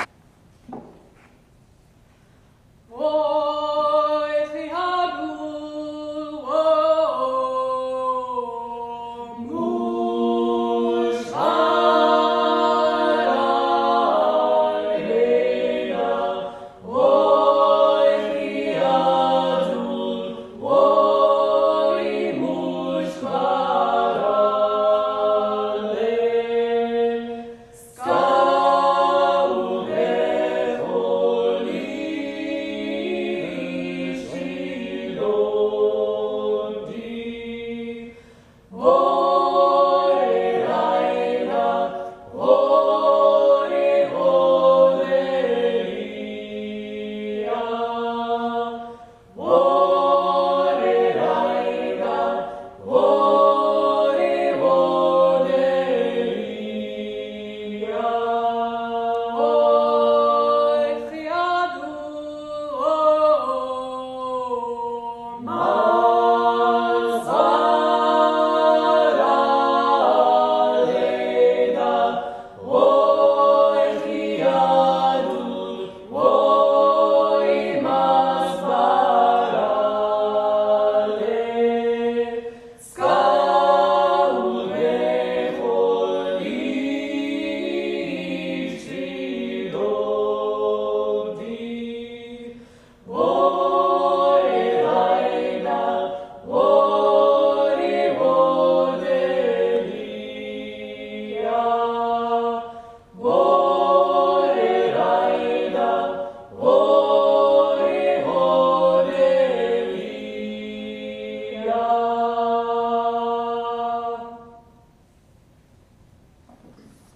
Woi Khiadul, traditional Georgian folksong (recorded by Akhalkhmebi in 2007)